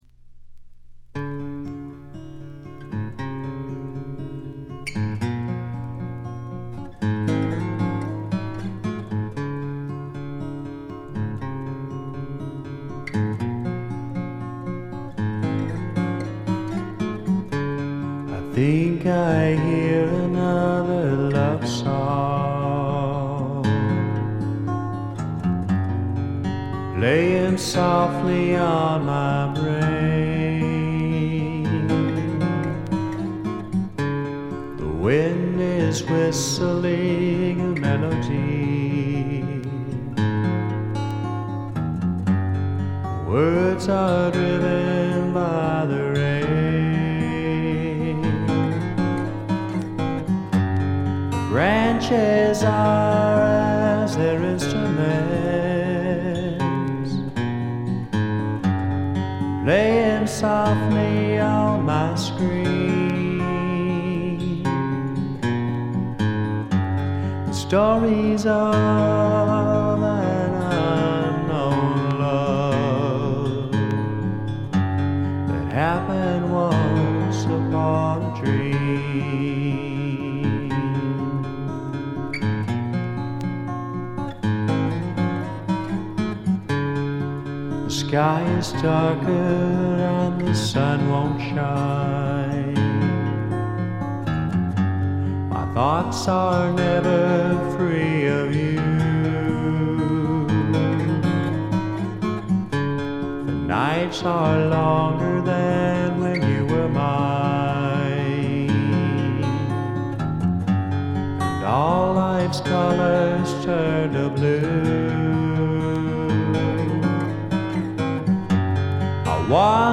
ほとんどノイズ感無し。
カントリー風味の曲とかファンキーな曲とかもありますが、クールでちょいメロウな曲調が特に素晴らしいと思います。
試聴曲は現品からの取り込み音源です。
Recorded at - Real To Reel , Garland, Texas